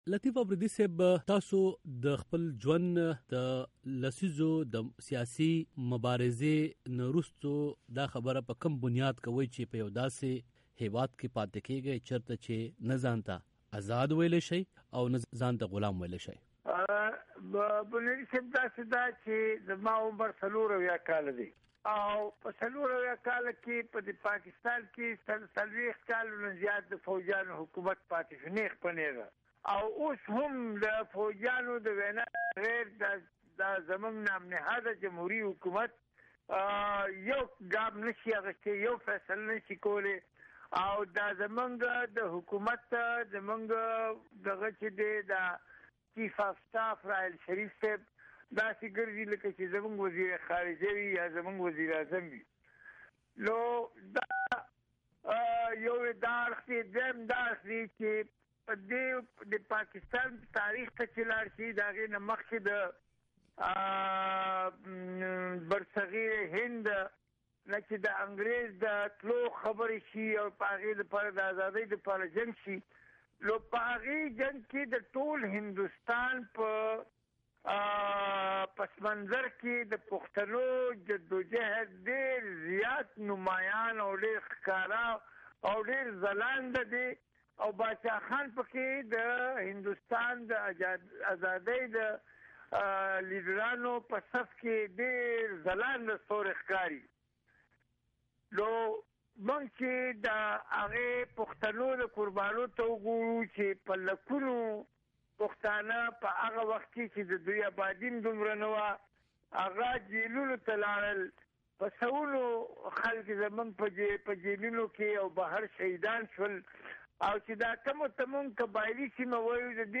ځانگړې مرکه